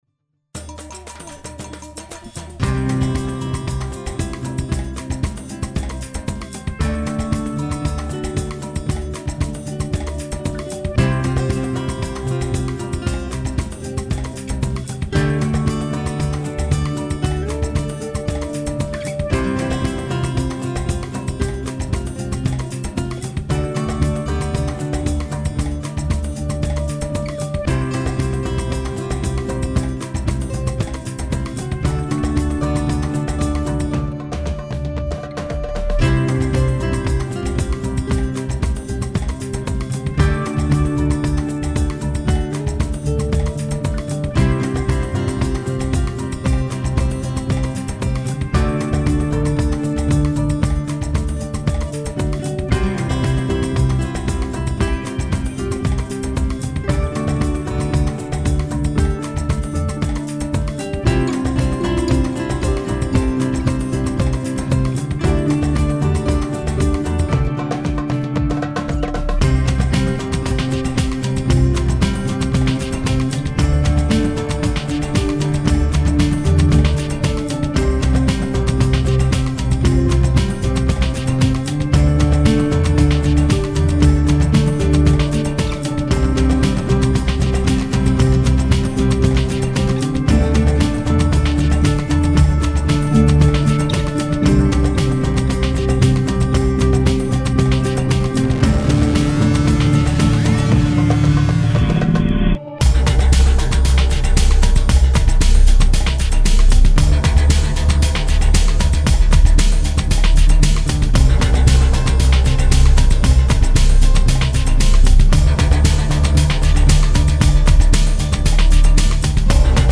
I grabbed some samples of a Samba rhythm and built off of that for the score. I, of course, threw a break beat over the samba to funk it up and then added some individual hits from a kit drum on top of that to give it some heft.
The full track starts out fairly organic and beautiful then takes a sudden turn for the darker before it ends back on the samba groove.
The music here is at 115 bpm and the video was shot at 14 frames per second.